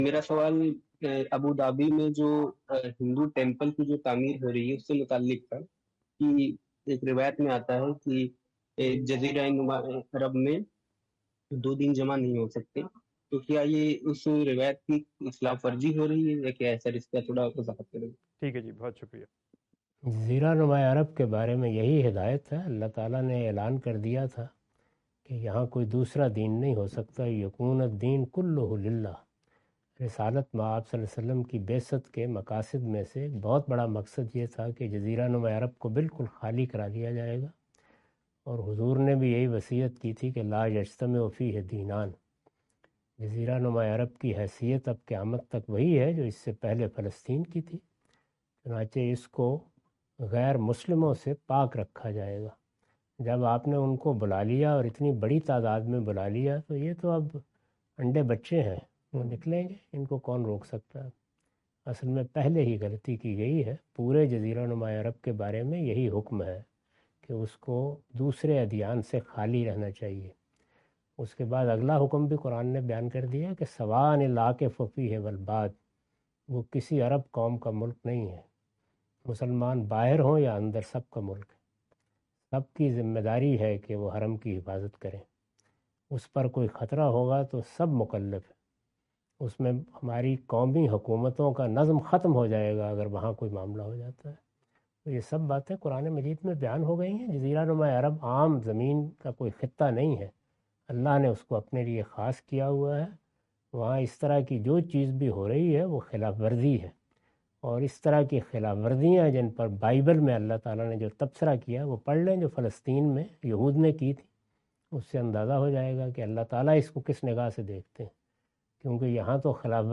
In this video, Mr Ghamidi answer the question about "Is the construction of a temple in Abu Dhabi a violation of the Prophet Muhammad's (PBUH) guidance?".
اس ویڈیو میں جناب جاوید احمد صاحب غامدی "کیا ابو ظہبی میں مندر کی تعمیر رسول اللہ ﷺ کی ہدایت کی خلاف ورزی ہے؟" سے متعلق سوال کا جواب دے رہے ہیں۔